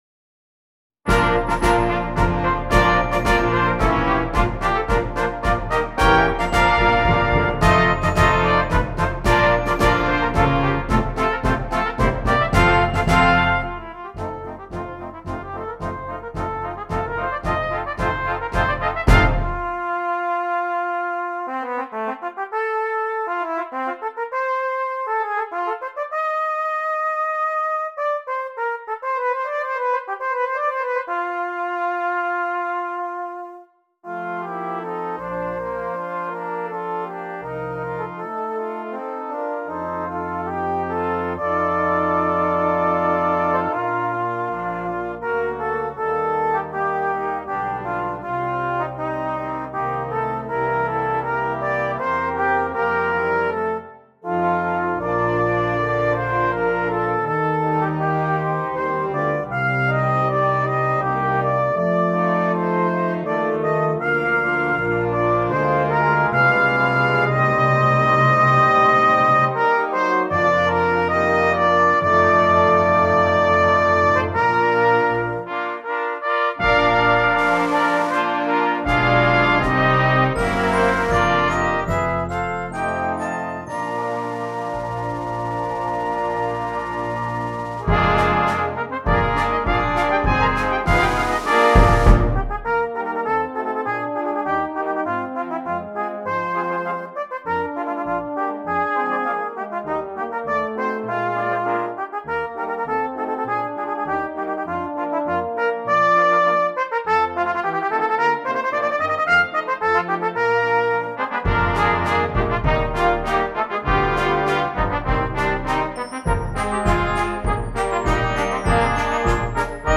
Brass Choir
This fun light piece is a great showpiece for the cornet.